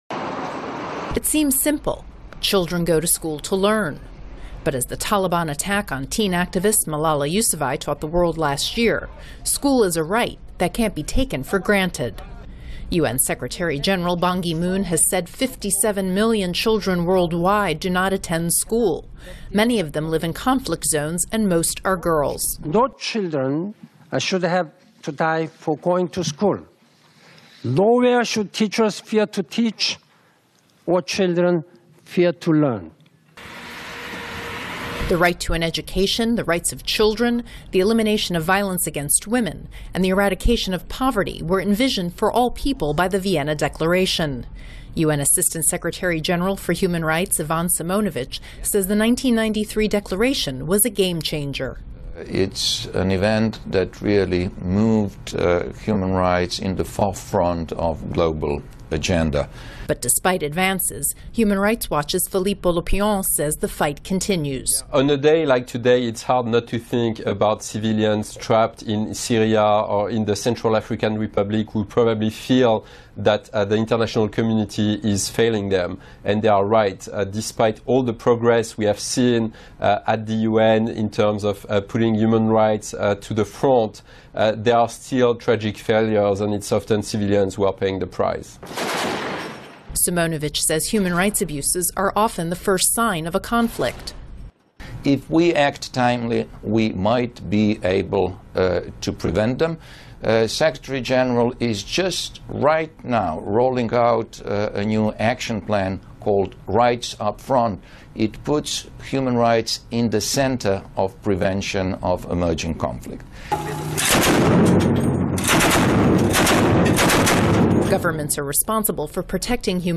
您现在的位置：VOA > VOA常速英语 > 12月份目录 > 联合国纪念国际人权日